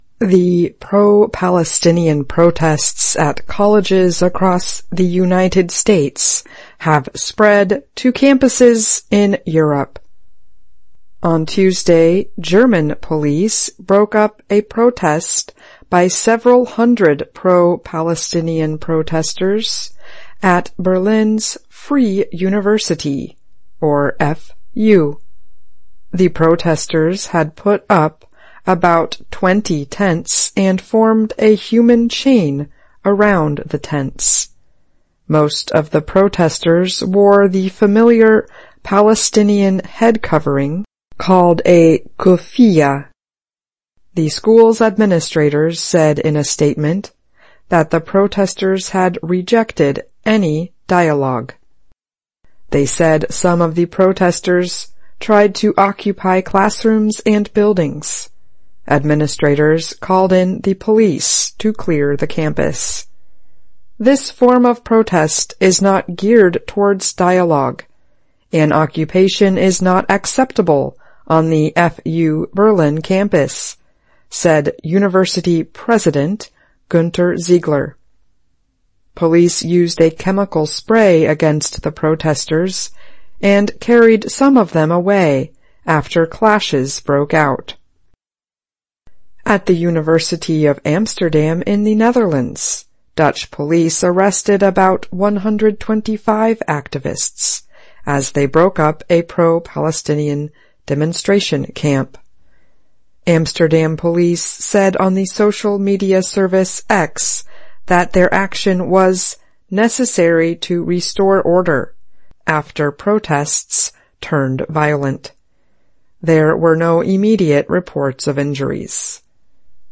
2024-05-08 [Education Report] US Campus Protests Spread to Europe
VOA慢速英语逐行复读精听提高英语听力水平